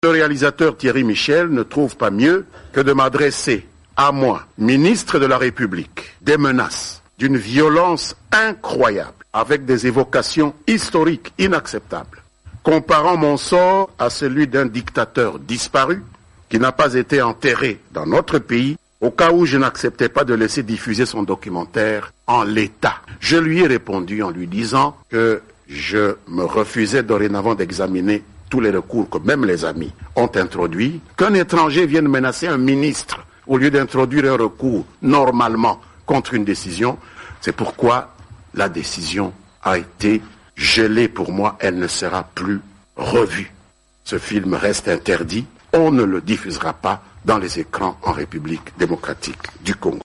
Lambert Mende, porte parole du gouvernement de RDC